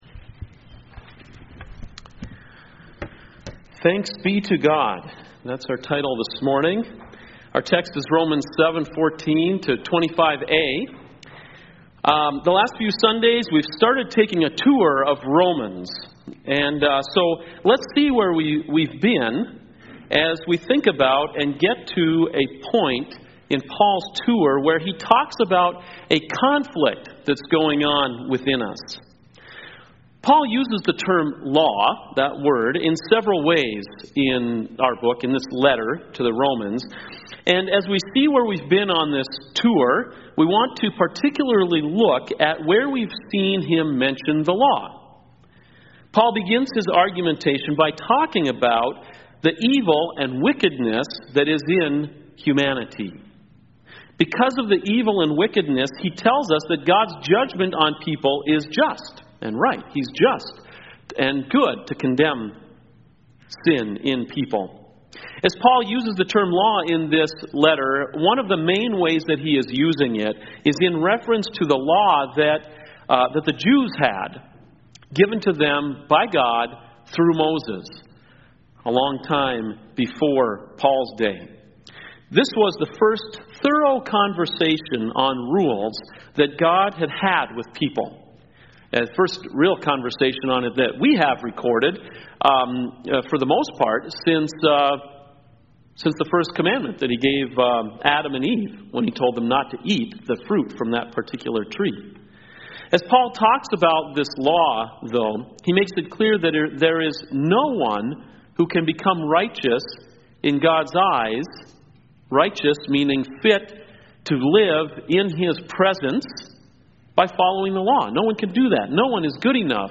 CoJ Sermons Thanks Be To God!